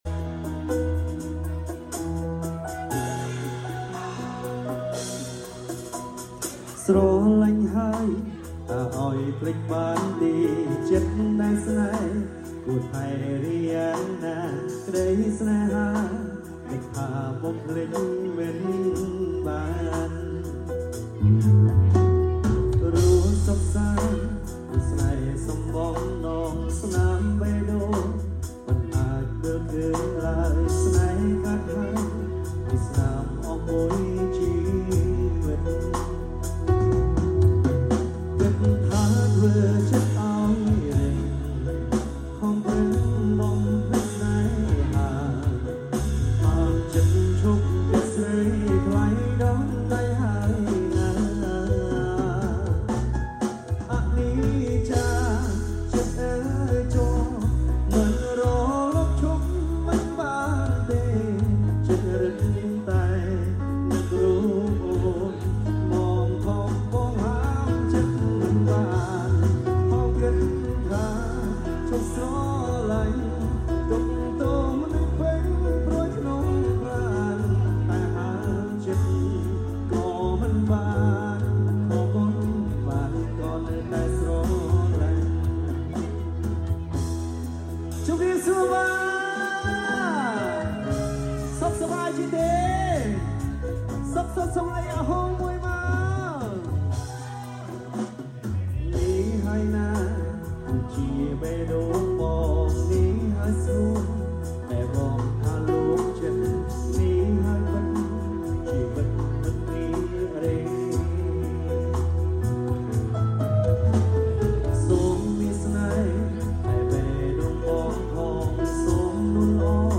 live band
acoustic